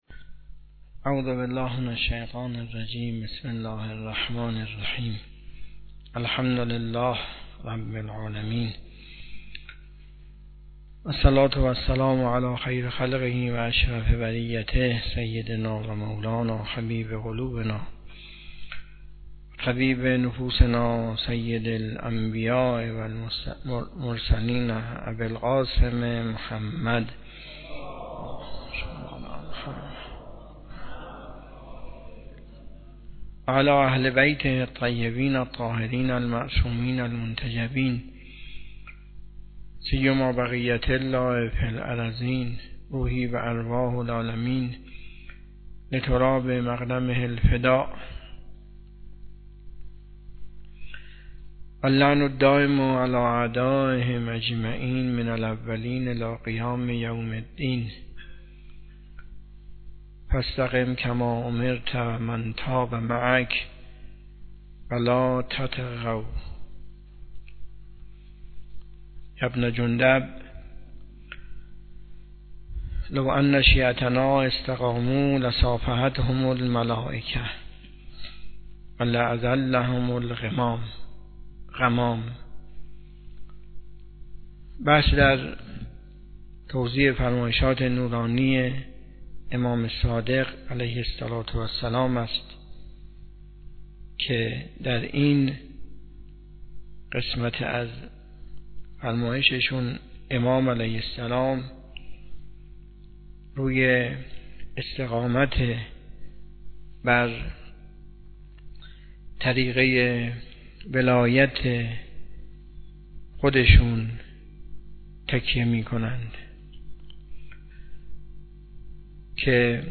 سخنرانی
حوزه علمیه معیر تهران